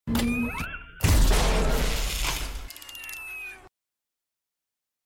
iron-man-repulsor_24731.mp3